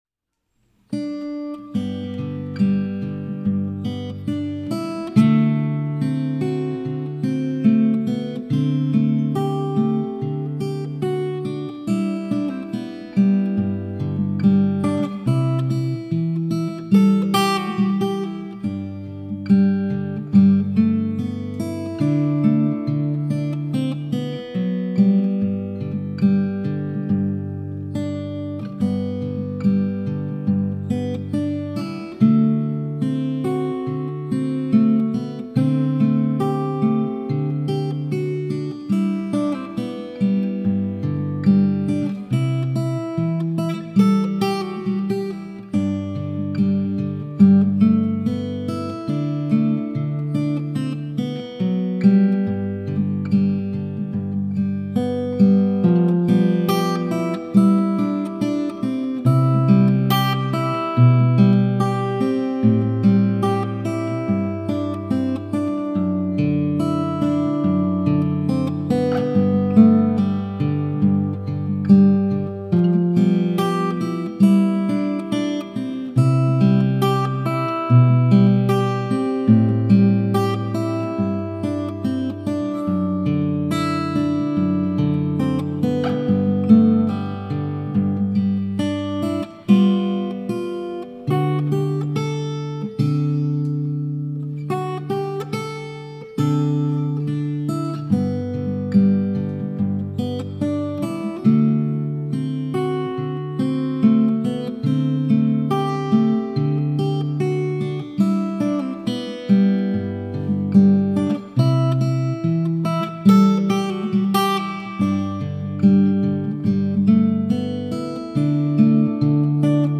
solo guitarist